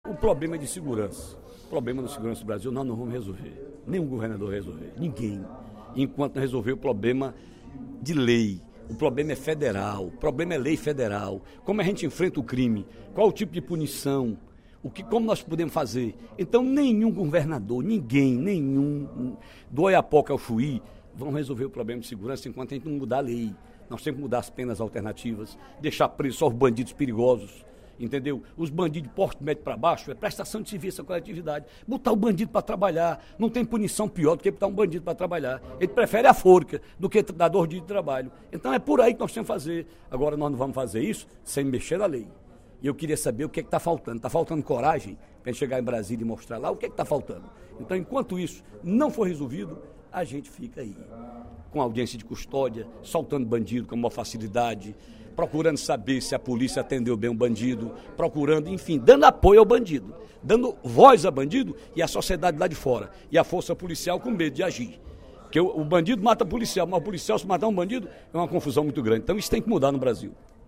O deputado Ferreira Aragão (PDT) levantou a questão da violência no Ceará, durante o primeiro expediente da sessão plenária desta quarta-feira (06/07). Ele informou que vai apresentar três emendas para “atualizar e fortalecer” o Conselho Estadual de Segurança Pública, pois a única forma de lidar com a violência é “alterar a legislação”.